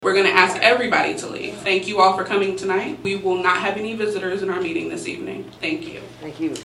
Tensions rose between the public during Wednesday’s USD 383 Manhattan-Ogden school board meeting.
Arguments continued until Board President Jurdene Coleman stepped in and closed the public comment.